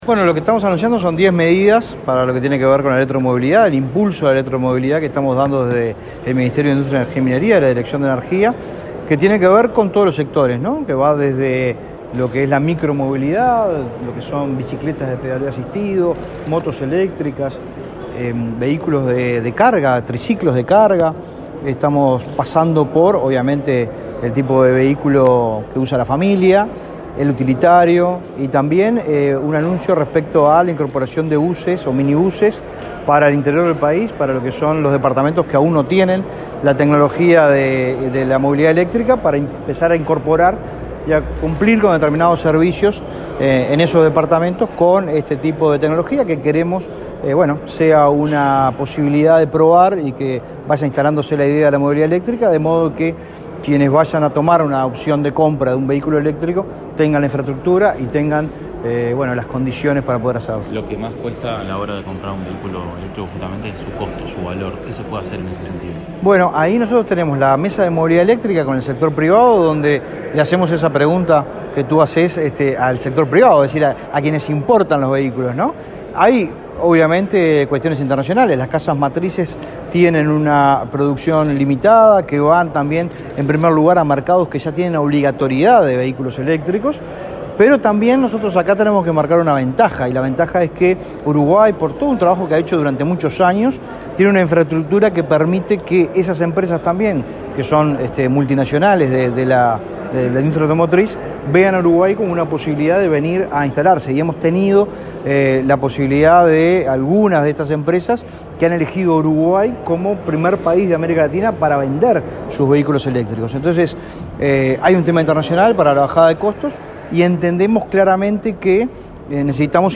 Declaraciones a la prensa del director nacional de Energía, Fitzgerald Cantero
Declaraciones a la prensa del director nacional de Energía, Fitzgerald Cantero 01/06/2022 Compartir Facebook X Copiar enlace WhatsApp LinkedIn El director nacional de Energía, Fitzgerald Cantero, dialogó con la prensa, luego de participar en el acto en el que el Ministerio de Industria informó acerca de varias medidas adoptadas para promover la movilidad eléctrica en Uruguay.